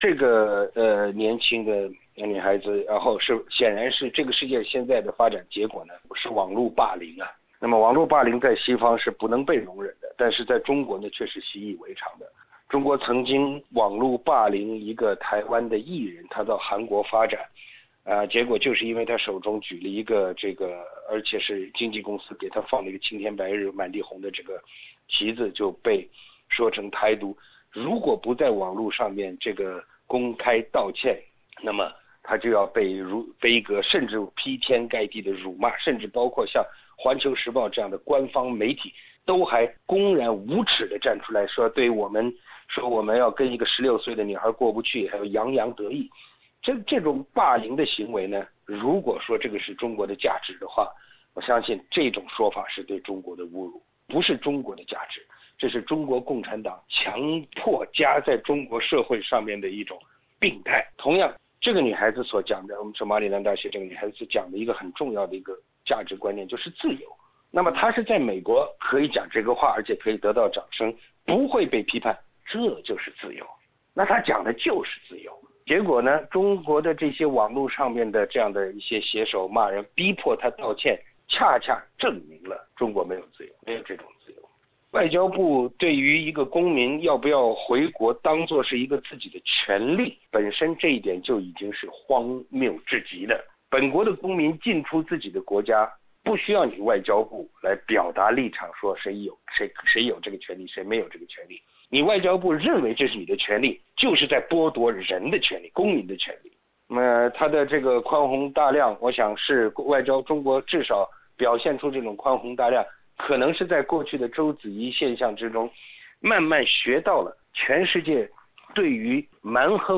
（本节目为嘉宾观点，不代表本台立场）